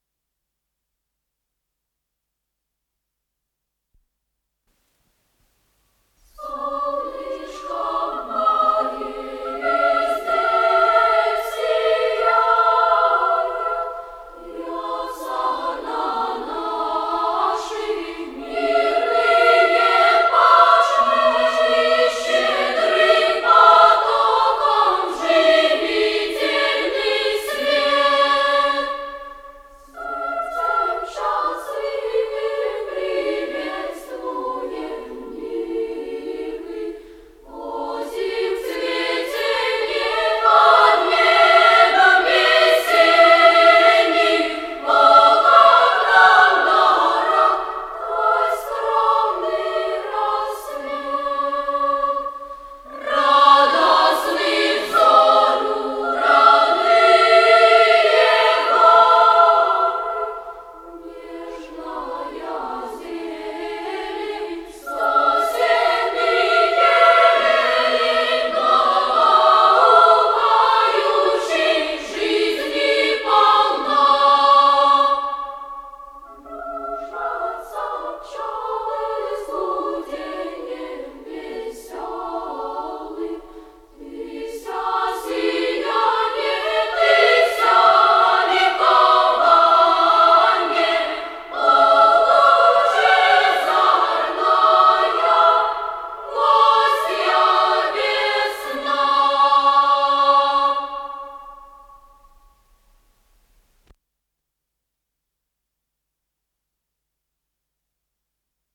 с профессиональной магнитной ленты
ИсполнителиБольшой детский хор Всесоюзного радио и Центрального телевидения
Художественный руководитель и дирижёр - Виктор Попов
ВариантДубль моно